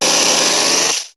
Cri de Dardargnan dans Pokémon HOME.